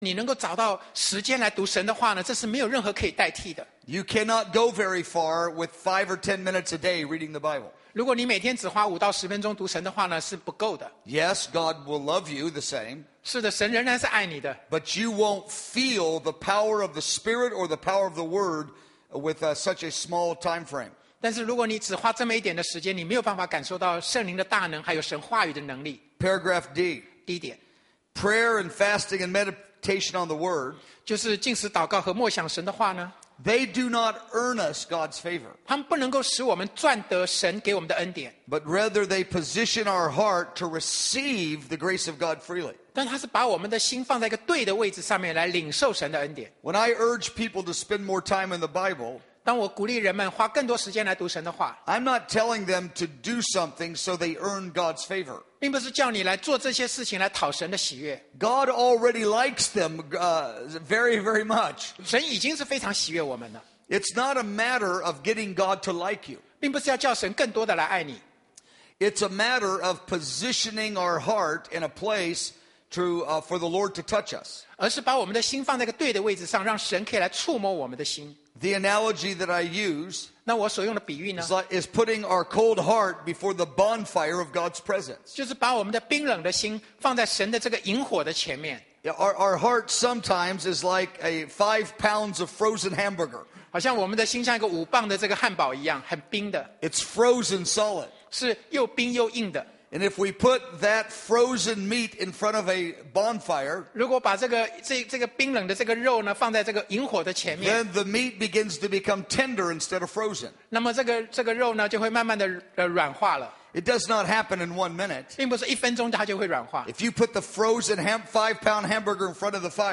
04证道系列1/与圣灵相交